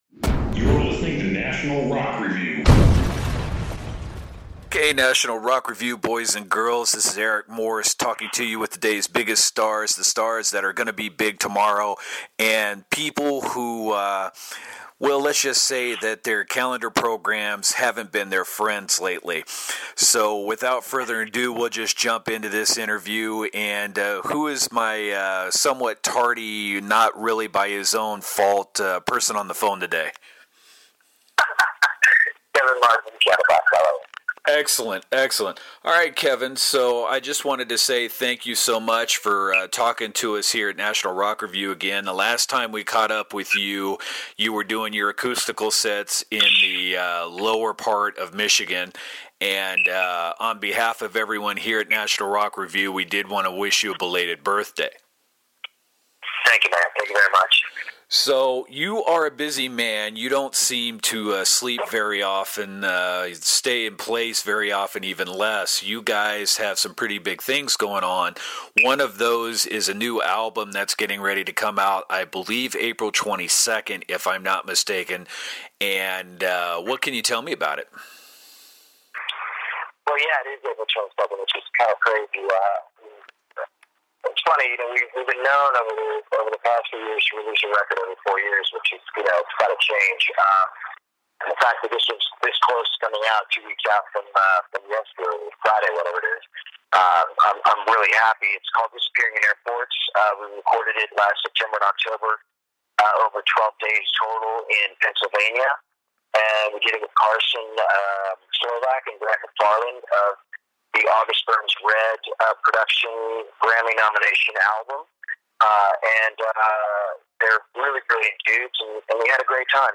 So, in full disclosure, with good/bad phone connection and not wanting to tax his money maker more then necessary, the audio for this interview could be better.